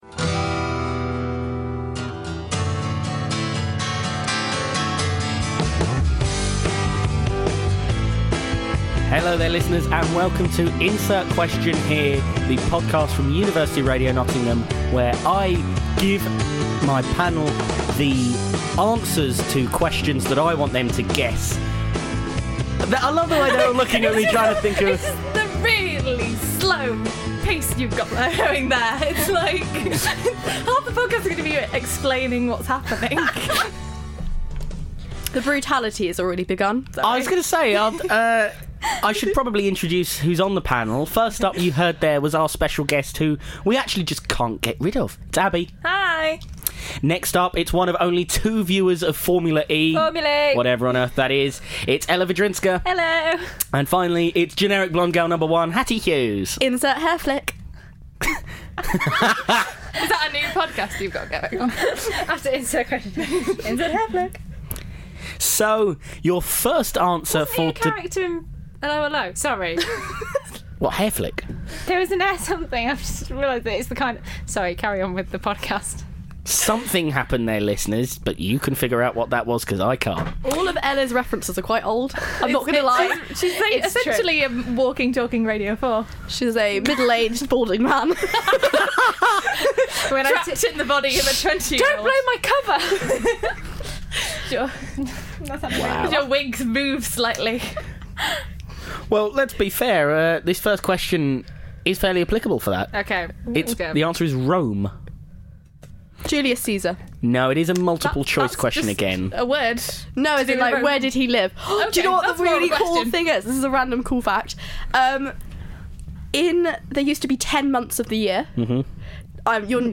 4 students. 1 pack of children's Trivial Pursuit cards.